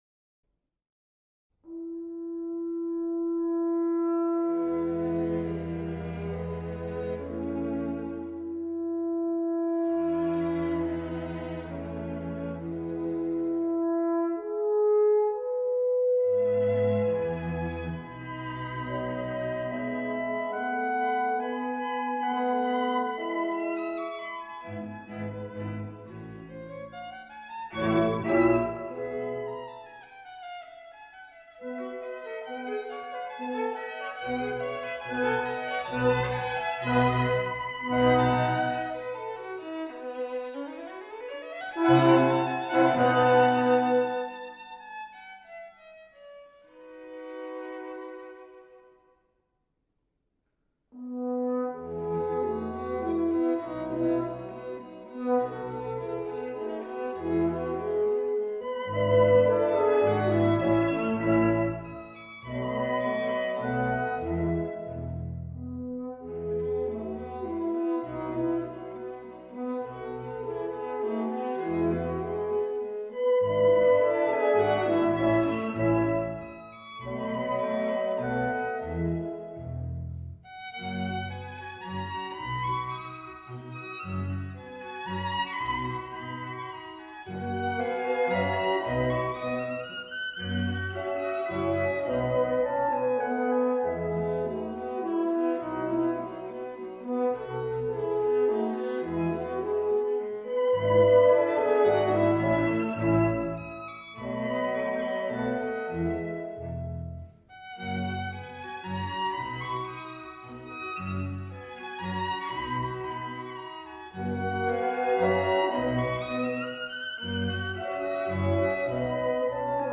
Violin, Viola, Cello, Bass, Clarinet, Horn & Bassoon